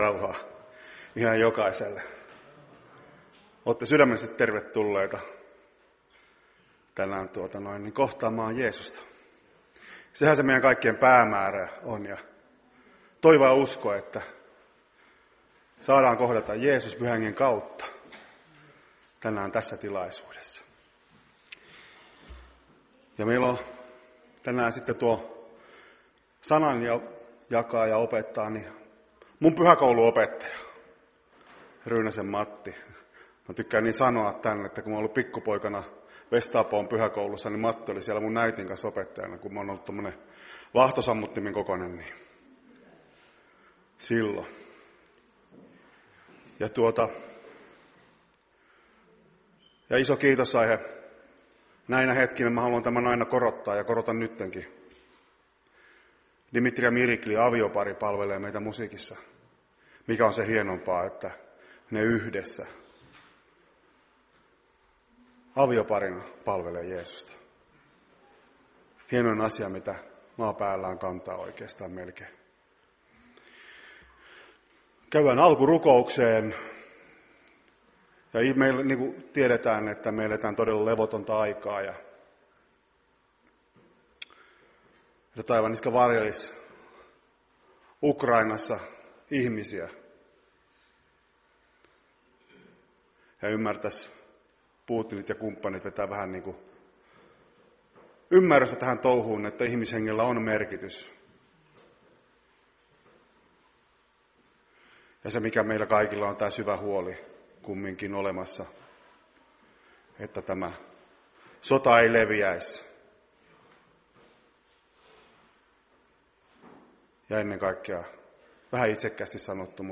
Iltapäivätilaisuus 27.2.2022
Iltapäiväkokous 27.2.2022